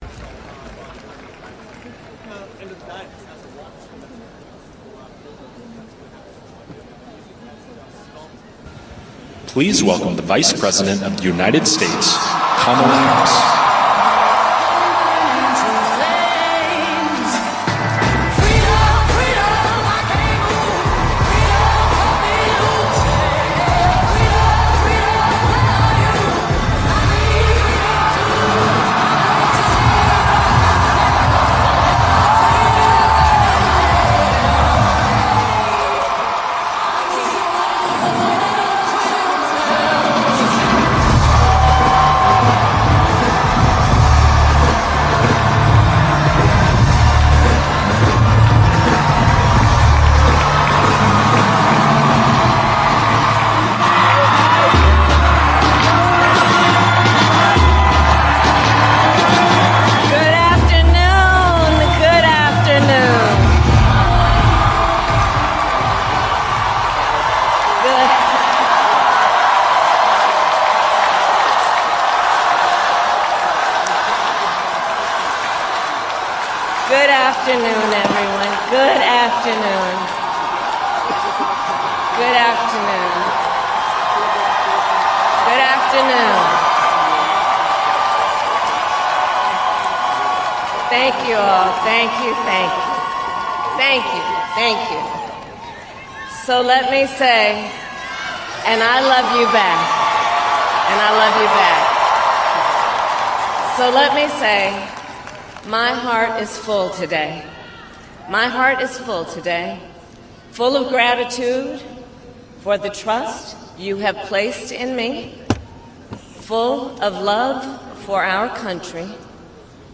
Kamala Harris concession speech
Kamala Harris delivers her concession speech after Donald Trump was elected the 47th president of the United States.